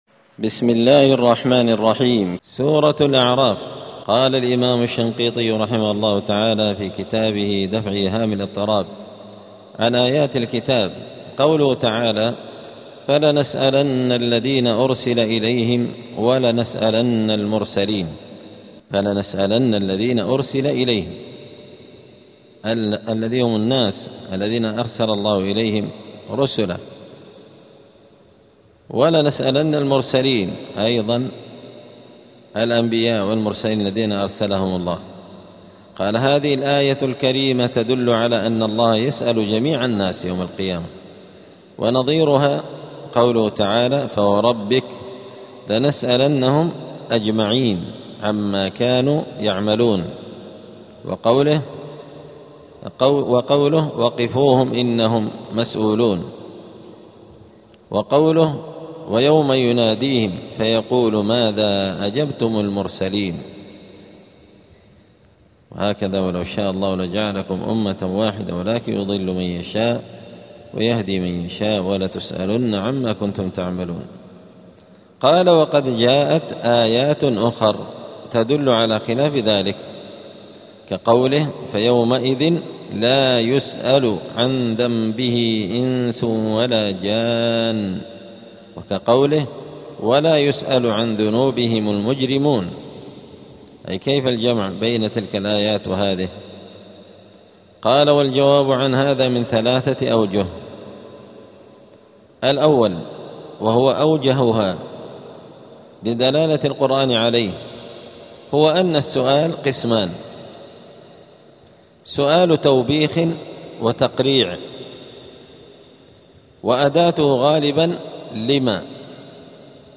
*الدرس الثالث والأربعون (43) {سورة الأعراف}.*
دار الحديث السلفية بمسجد الفرقان قشن المهرة اليمن